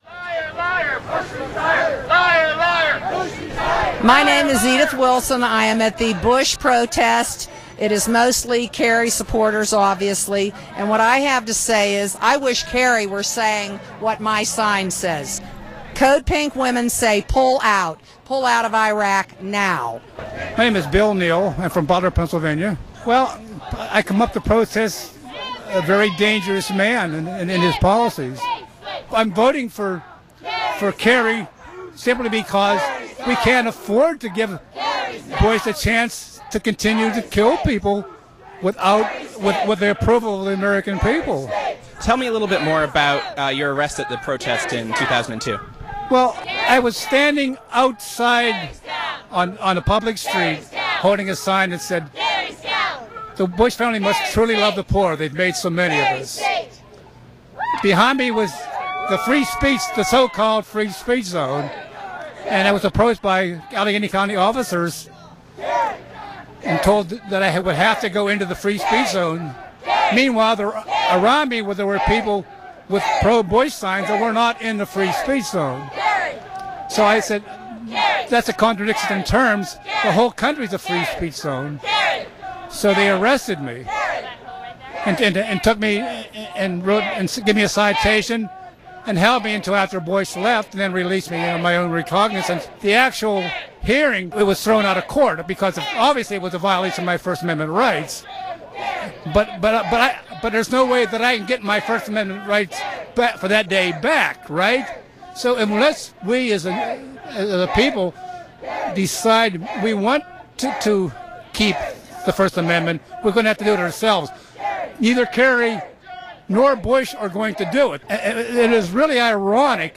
Interviews at Bush Protest
Interviews at Bush Protest on Saturday, July 31. This aired on the IMC Pittsburgh Radio Show Tuesday, August 3.
About 100 Bush protestors gathered at the David Lawrence Convention Center on Saturday to protest George Bush, who was attending a fundraiser in the building. Chanting John Kerry's name and "Liar, Liar, Bush Retire," they drew cheers and honks from passing motorists.
bush_protest.ogg